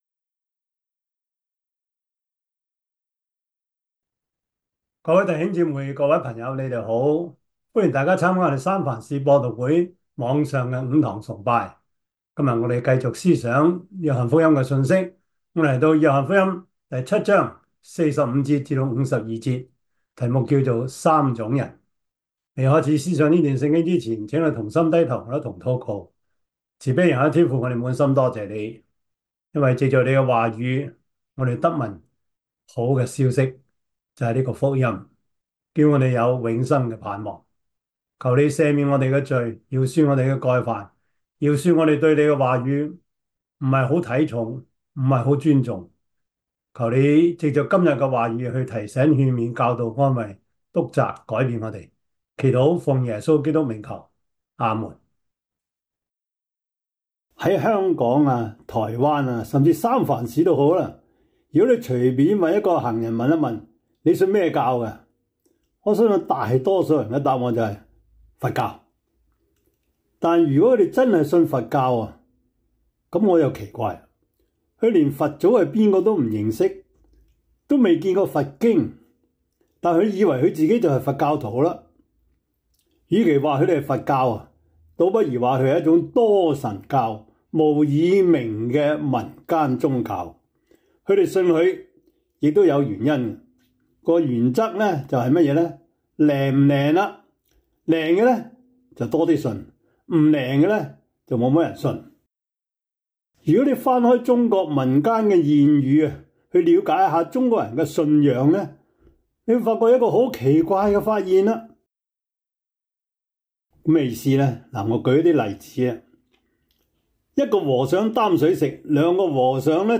約翰福音 7:45-52 Service Type: 主日崇拜 約翰福音 7:45-52 Chinese Union Version
Topics: 主日證道 « 家庭維生素 第四十六課: 核武的威脅 (1) »